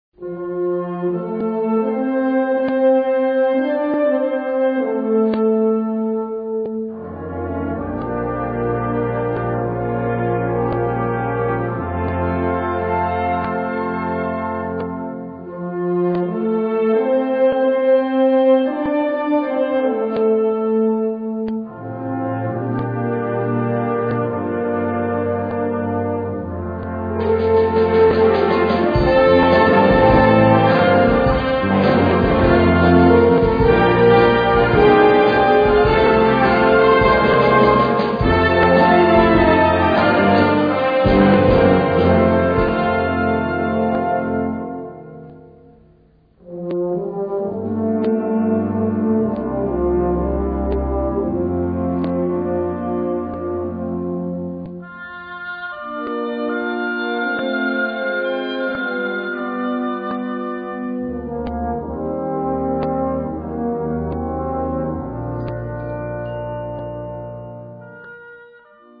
Unterkategorie Suite
Besetzung Ha (Blasorchester)